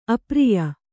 ♪ apriya